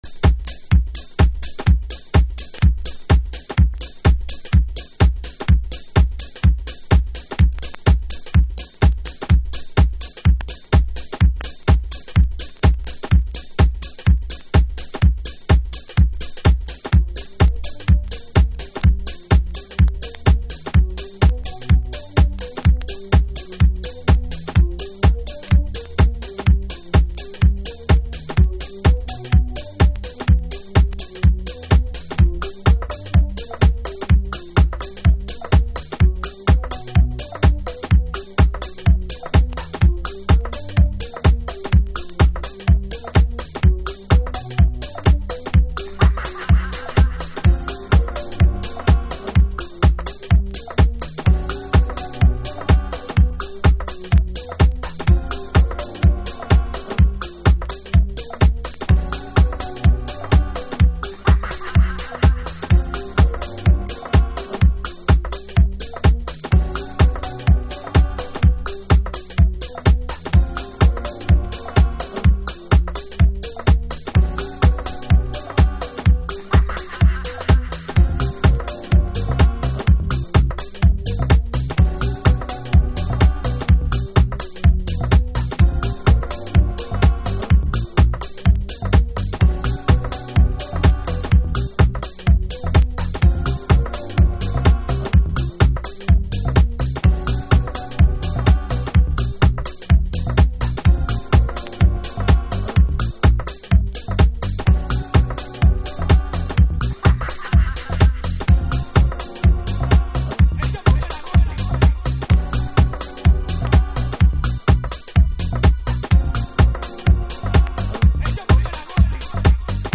House / Techno
シンプルかつファンクショナルな2TRAX。